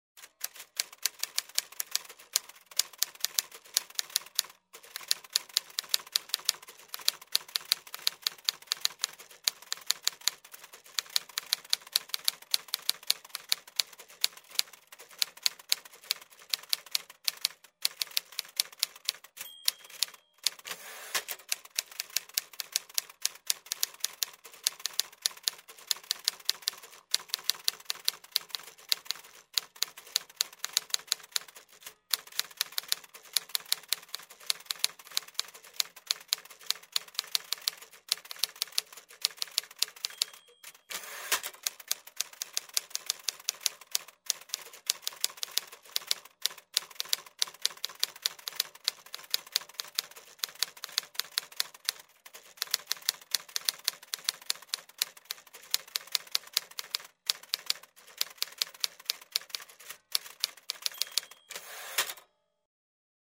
Звуки печатания
Звук печатной машинки во время набора текста